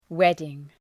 Προφορά
{‘wedıŋ}